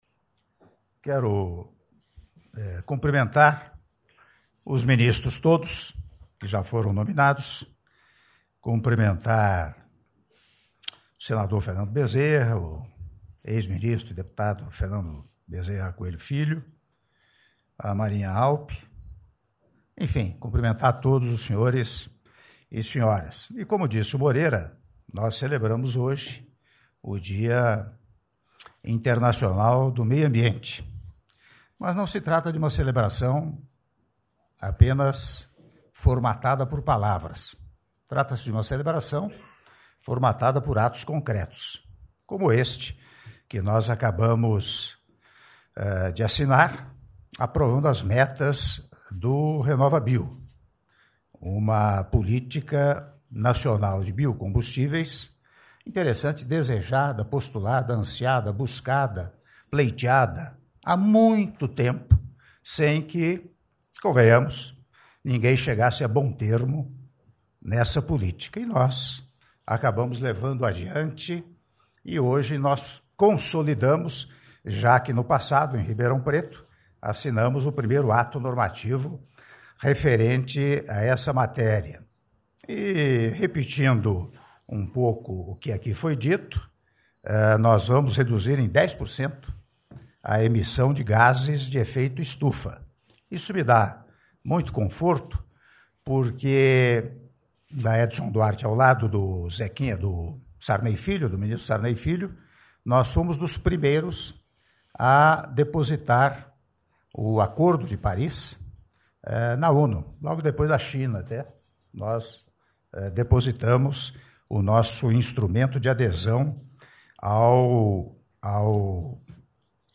Áudio discurso do Presidente da República, Michel Temer, durante cerimônia de aprovação das metas da RenovaBio - Palácio do Planalto (07min39s)